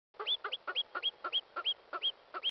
dishuguaixiao.mp3